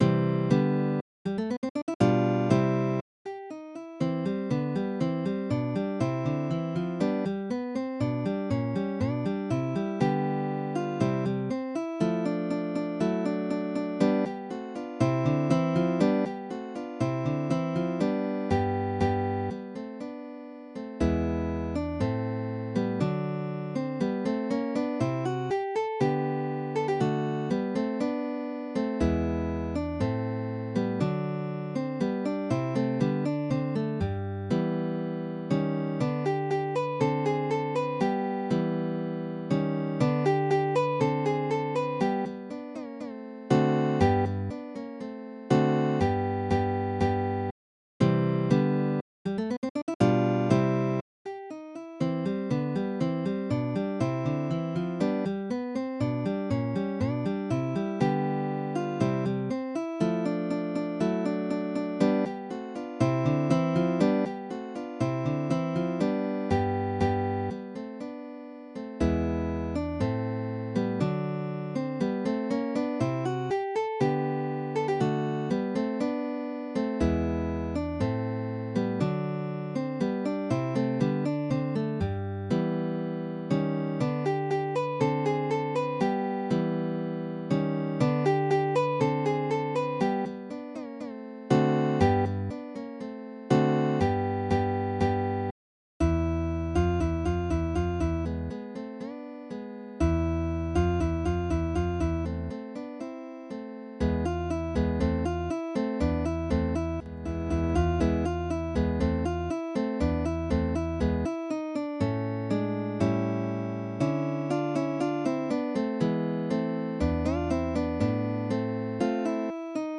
Midi音楽が聴けます 3 170円